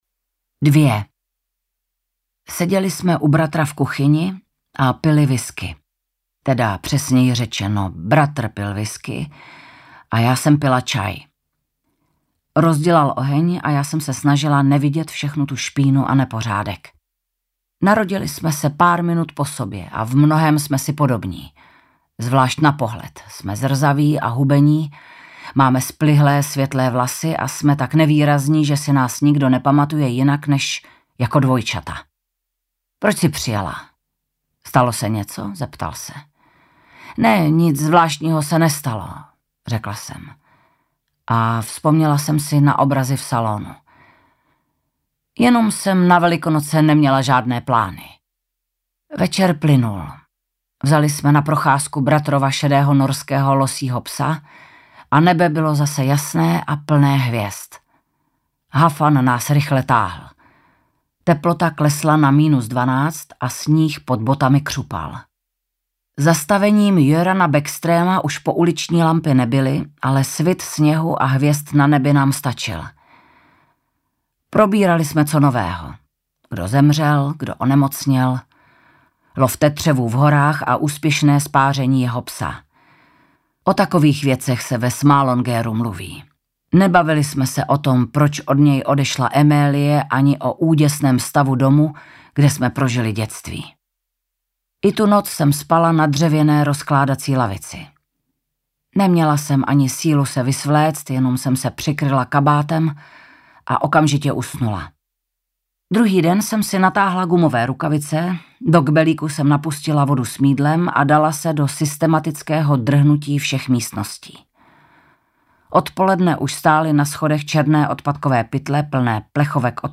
Audiobook
Read: Vanda Hybnerová